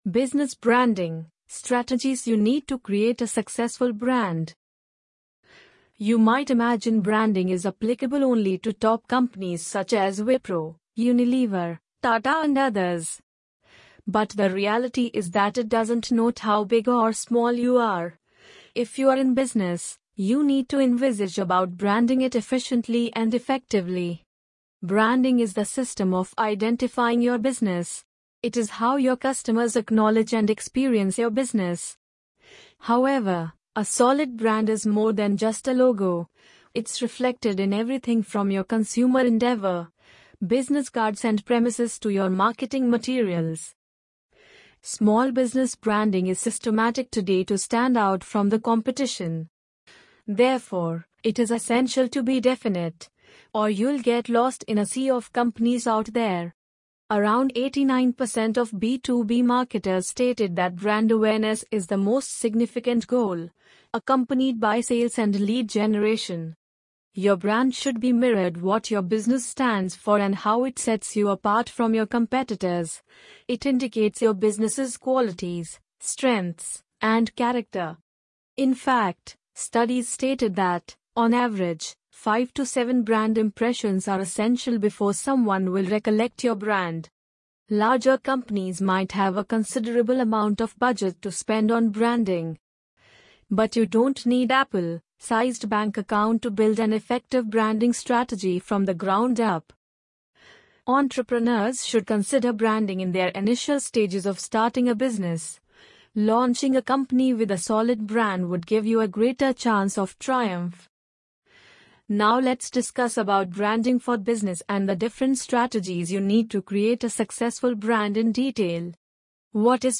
amazon_polly_5738.mp3